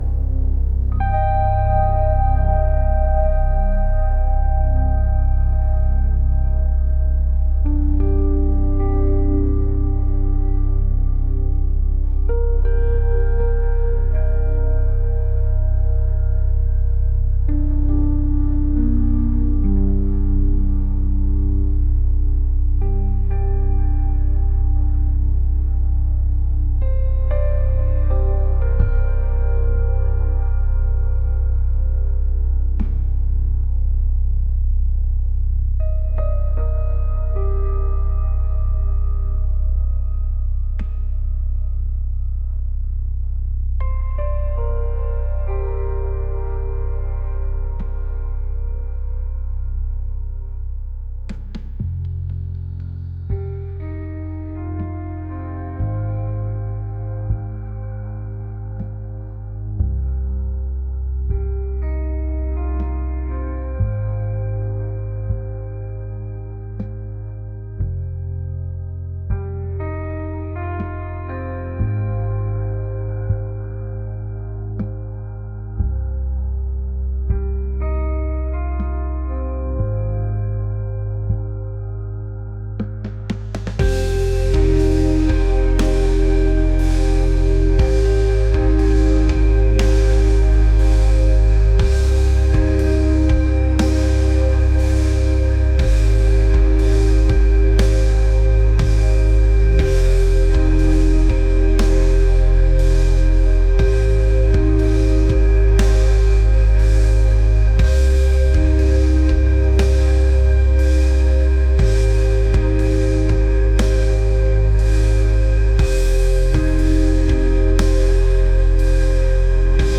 atmospheric